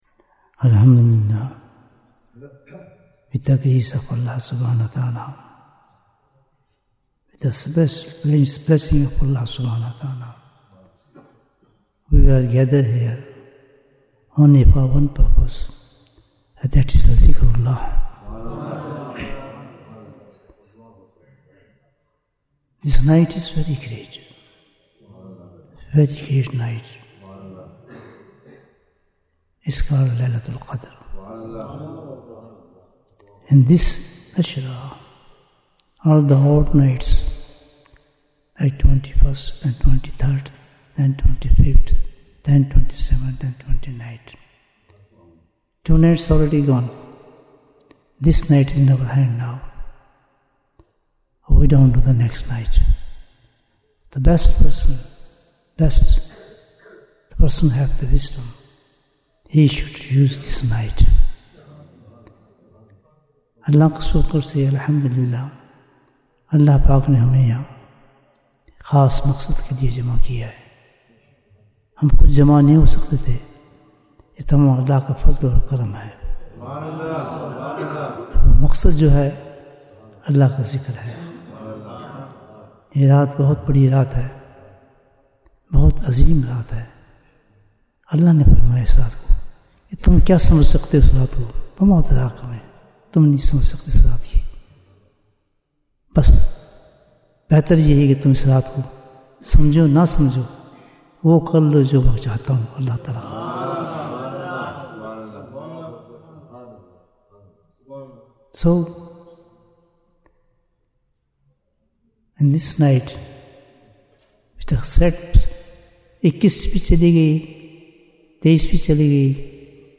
Bayans Clips Naat Sheets Store Live Jewels of Ramadhan 2026 - Episode 32 Bayan, 21 minutes 13th March, 2026 Click for Urdu Download Audio Comments Jewels of Ramadhan 2026- Episode 32 Tonight is the night of Laylatul Qadr.